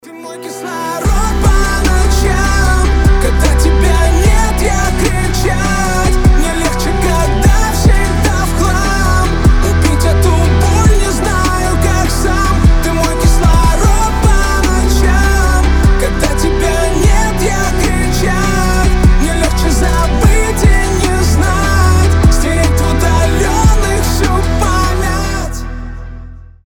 • Качество: 320, Stereo
мужской голос
лирика